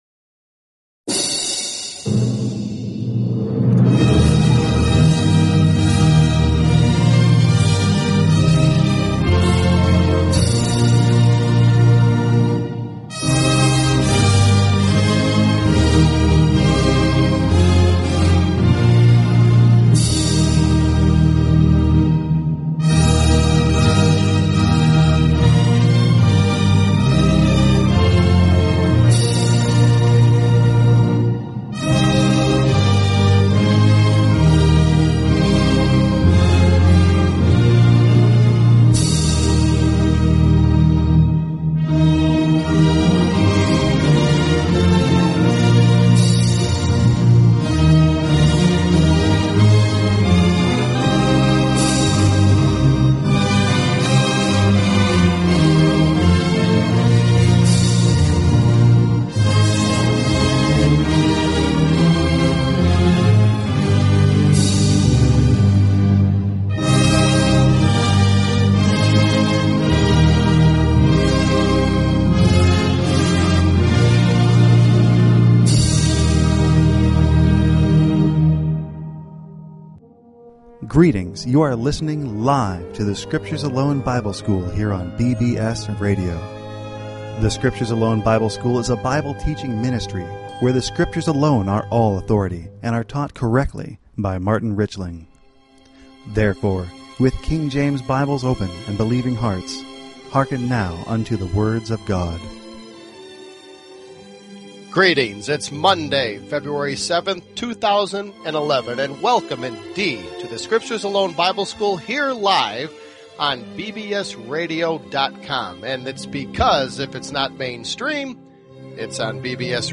Talk Show Episode, Audio Podcast, The_Scriptures_Alone_Bible_School and Courtesy of BBS Radio on , show guests , about , categorized as